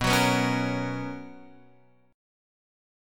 B Major 7th Suspended 2nd